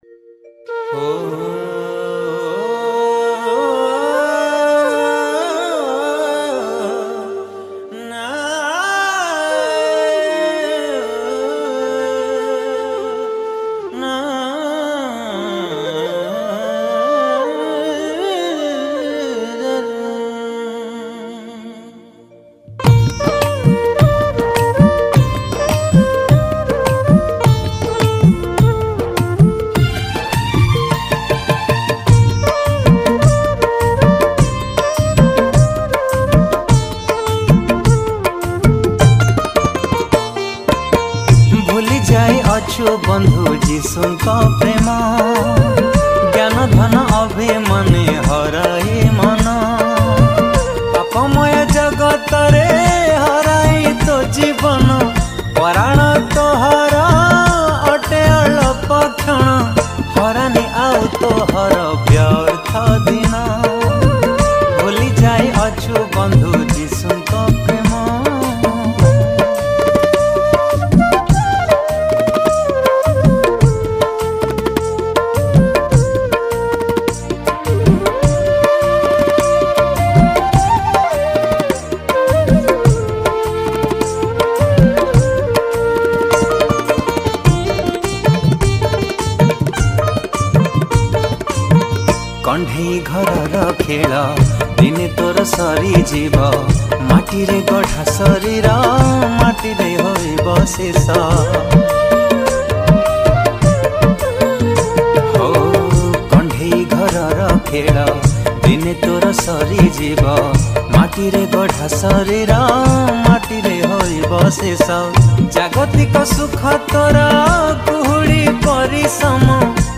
Home  / Odia Christian Song / Odia Christian Song 2024